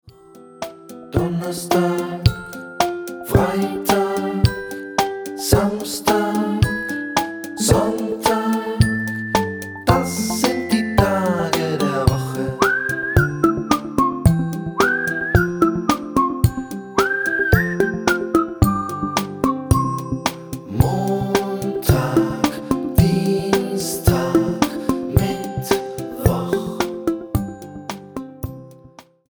Kinderlieder zur sprachlichen Frühförderung